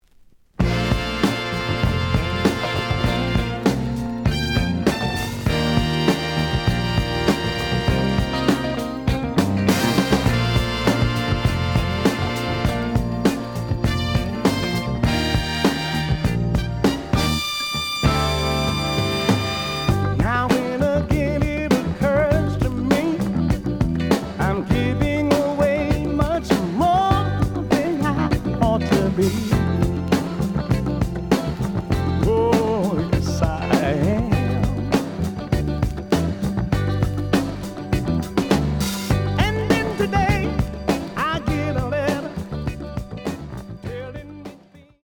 The audio sample is recorded from the actual item.
●Genre: Funk, 70's Funk
Slight edge warp.